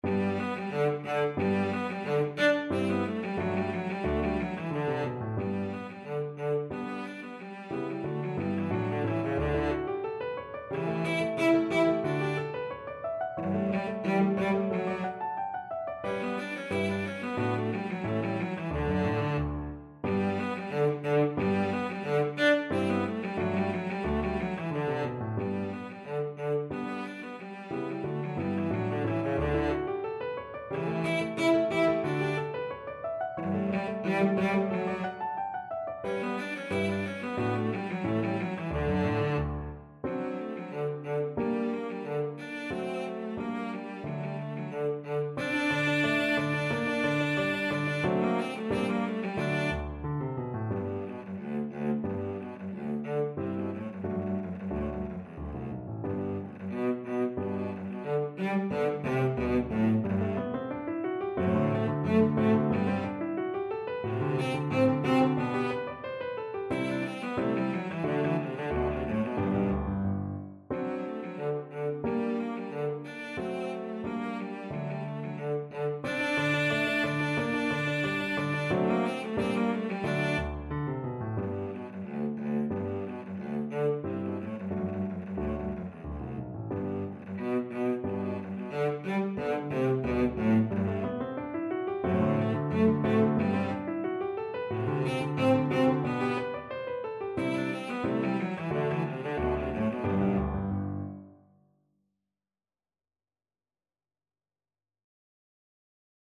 2/2 (View more 2/2 Music)
Spiritoso Spiritoso = 180
Classical (View more Classical Cello Music)